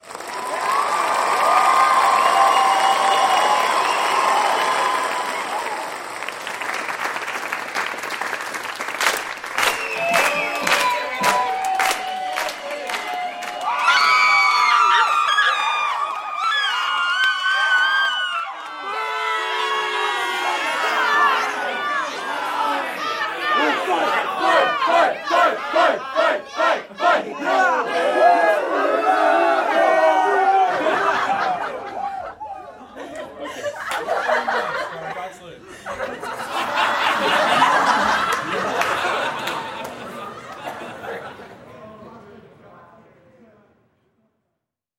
音效-自媒体短视频必备人群欢呼声掌声嘘声起哄声无损音效
114个人群欢呼声掌声嘘声起哄声Big Room声音，如欢呼声，掌声，lauqhter，嘘声，hecklinq等，形成了各种人群。